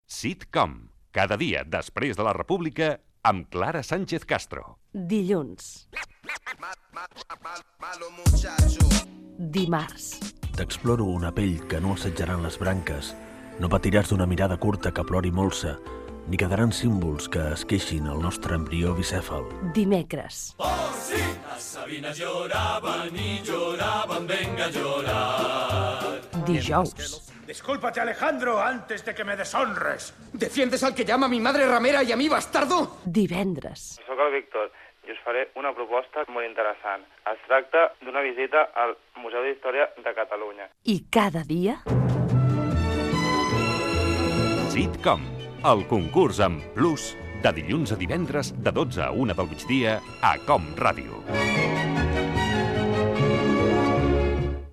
Promoció del programa concurs